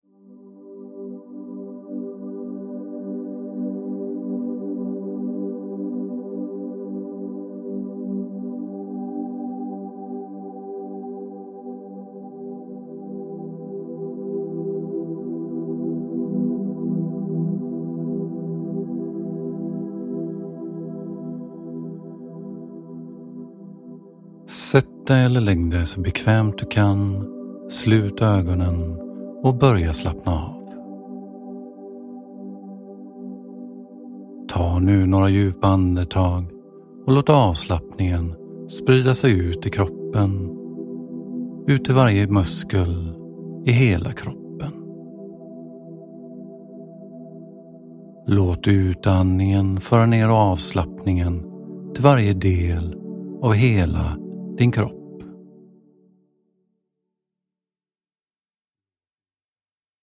Beslutsamhet – Ljudbok – Laddas ner